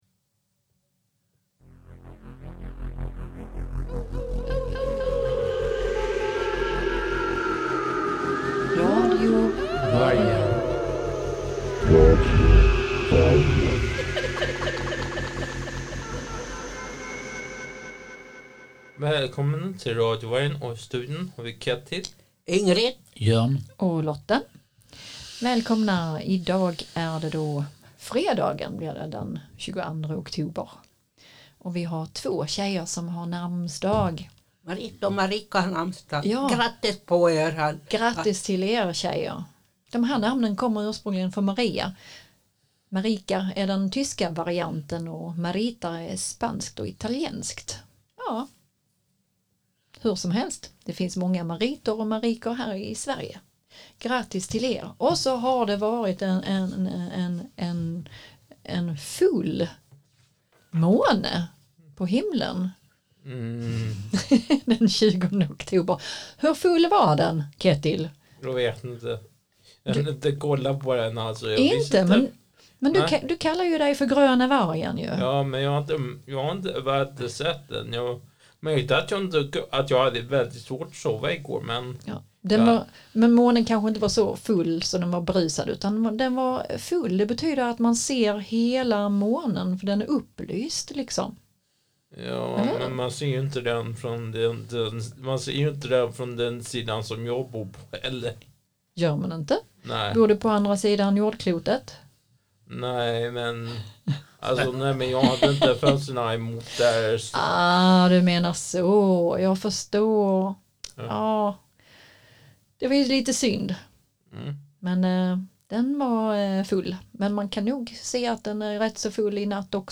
Vi varvar med önskelåtar från 60- och 70-talet.